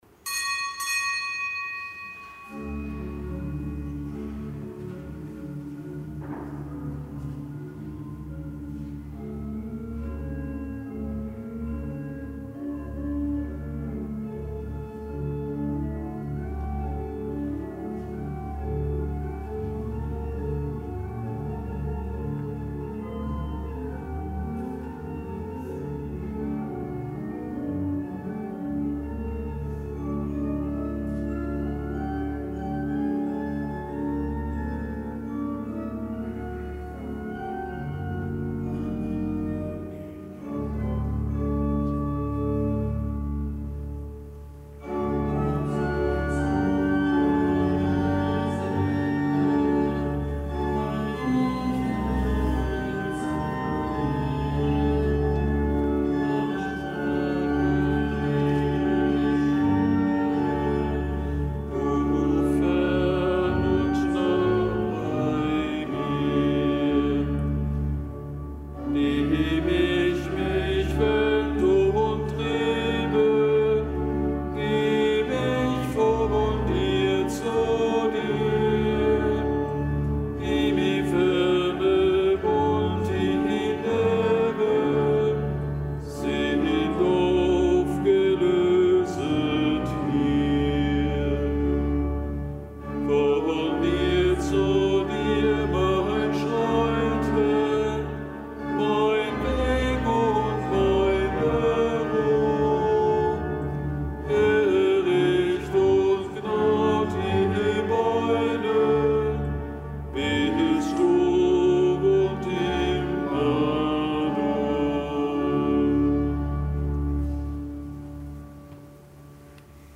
Kapitelsmesse aus dem Kölner Dom am Samstag der dritten Fastenwoche.